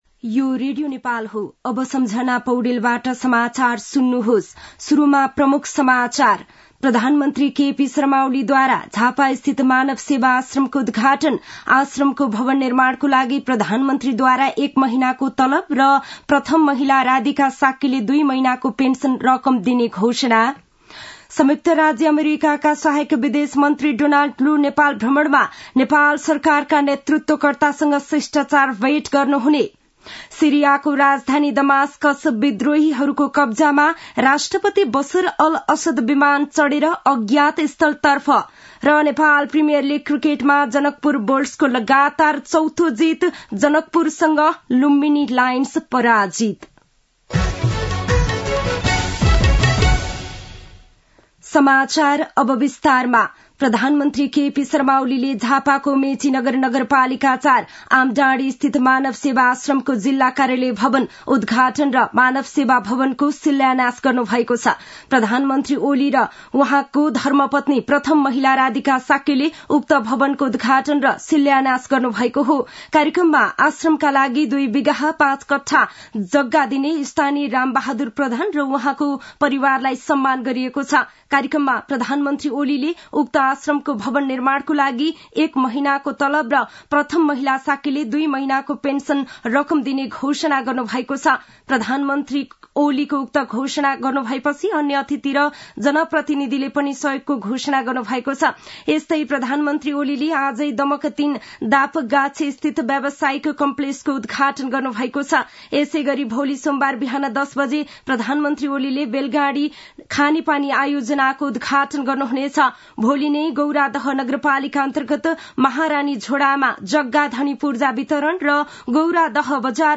दिउँसो ३ बजेको नेपाली समाचार : २४ मंसिर , २०८१
3-pm-nepali-news-1-5.mp3